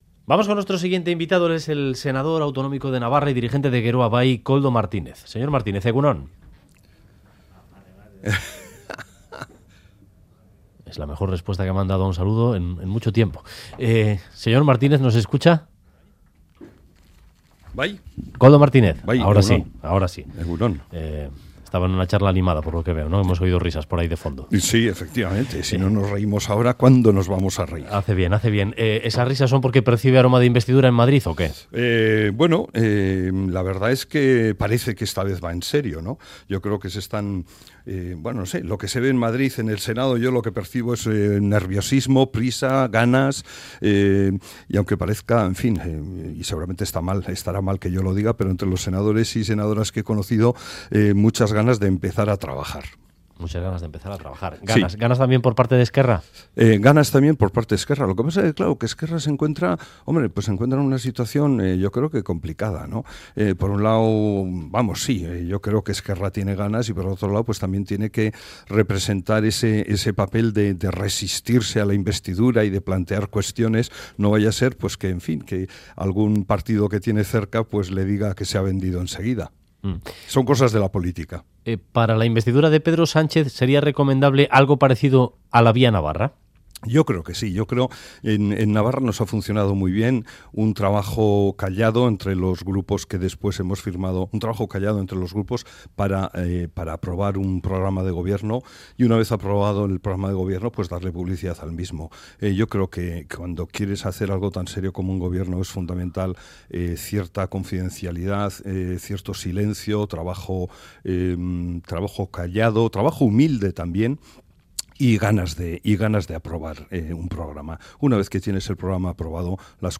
Audio: El senador de Geroa Bai observa a EH Bildu en la "moderación" por lo que confía en que unan sus votos a las del gobierno de Navarra para aprobar las cuentas.